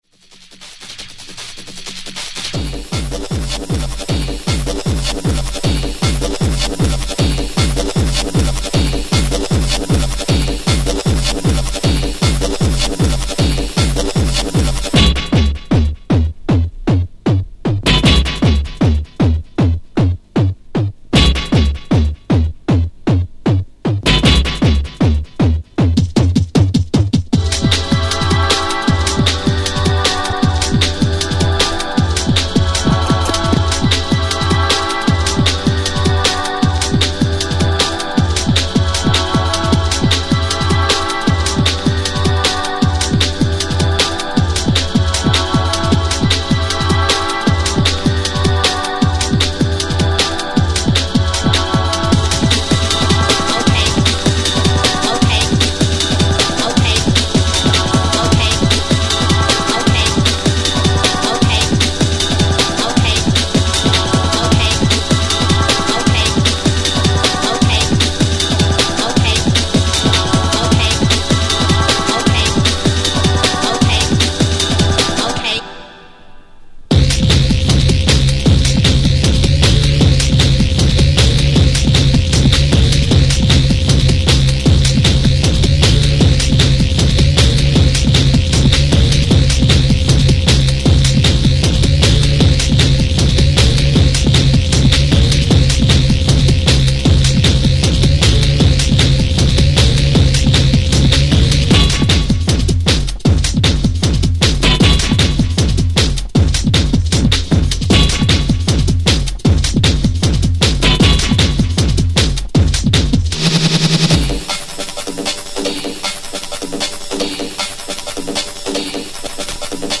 UK Hardcore